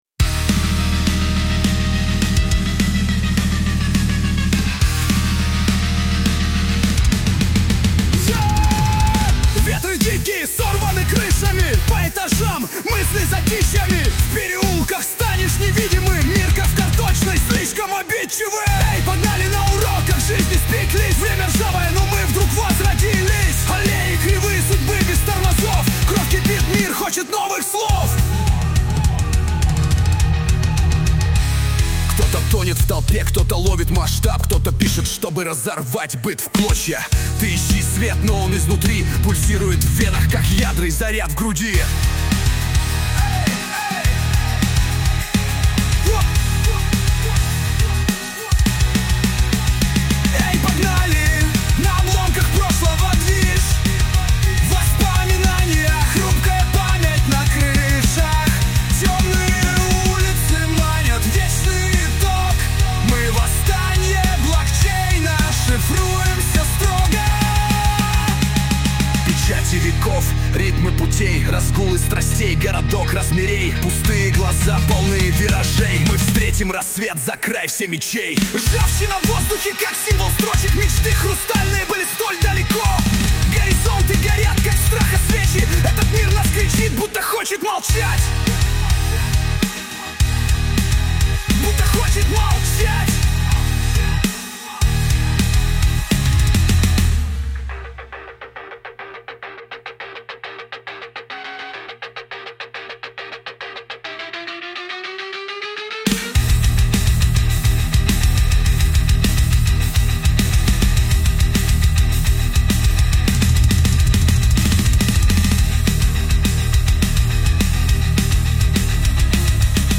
RUS, Rock, Metal, Folk, Funk, Rap | 17.03.2025 17:47